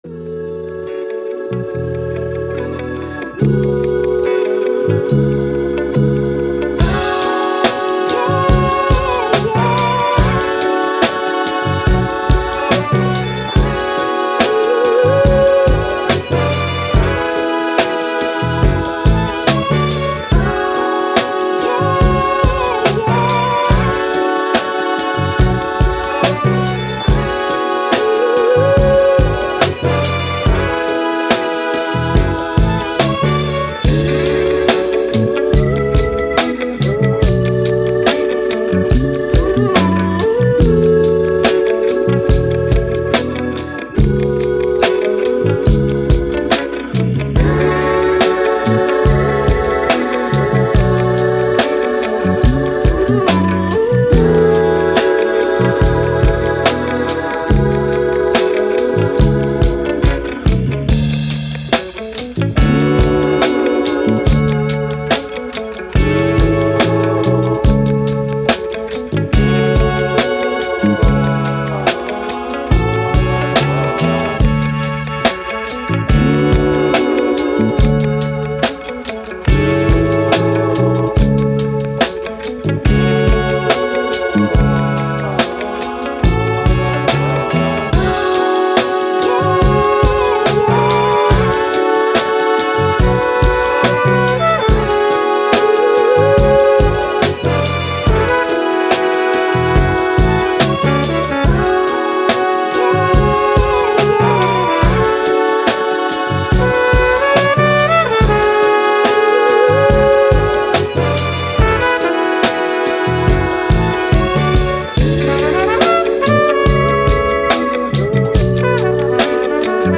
Soul, Vintage, Vibe, Chill